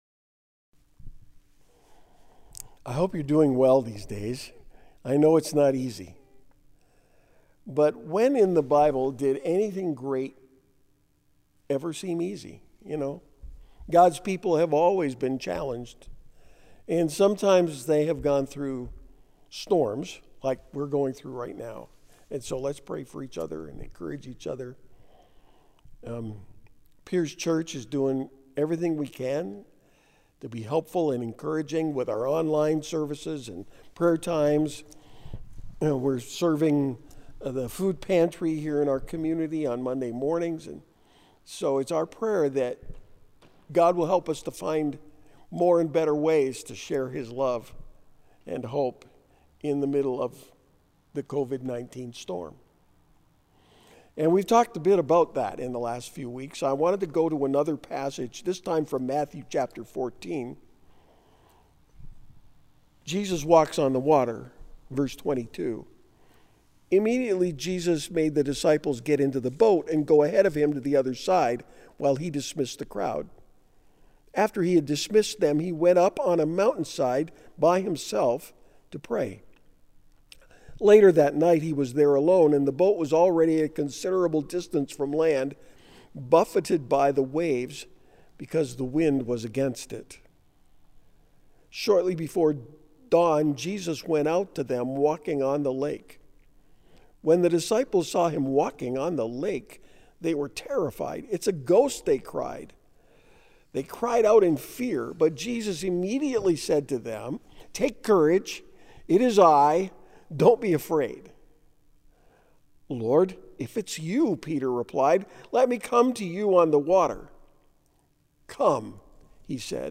Sermon-5-10-20.mp3